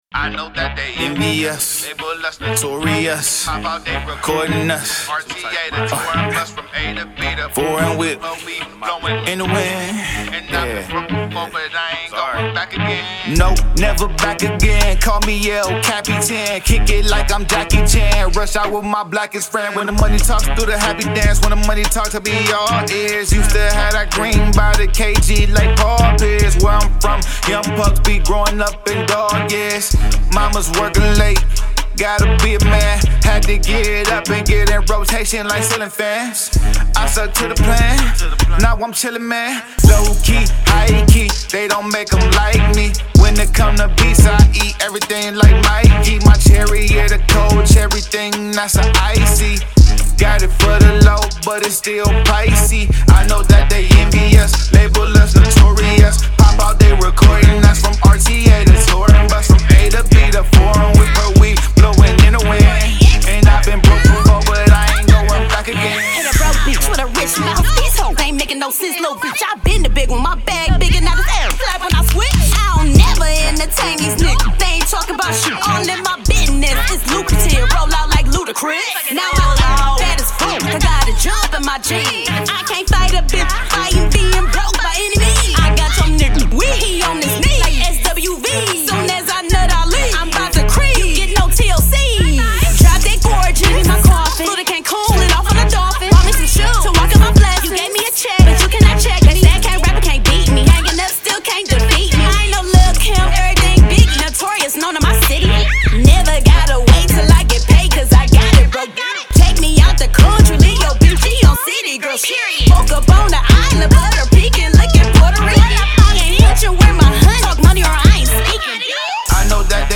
Genre: Hip Hop/Rap.